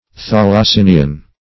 Search Result for " thalassinian" : The Collaborative International Dictionary of English v.0.48: Thalassinian \Thal`as*sin"i*an\, n. (Zool.) Any species of Thalassinidae , a family of burrowing macrurous Crustacea, having a long and soft abdomen.